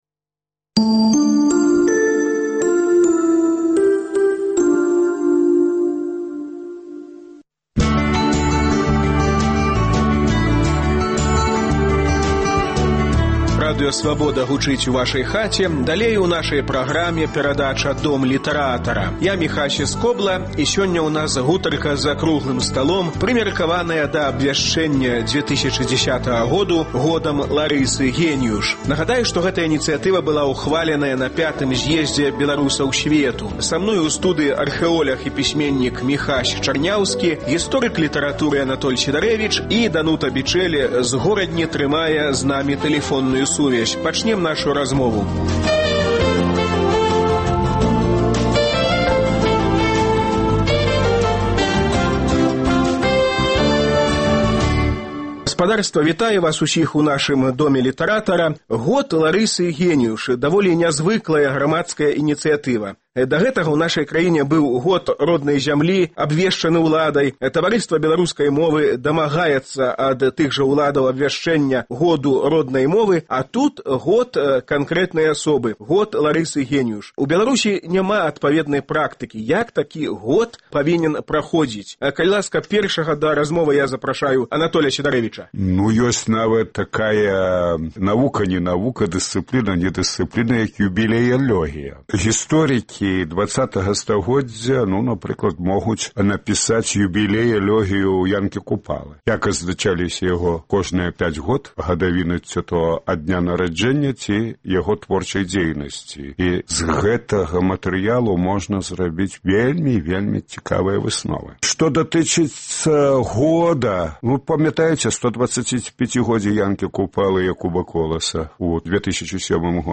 Гутарка за круглым сталом, прымеркаваная да абвяшчэньня 2010 году годам Ларысы Геніюш.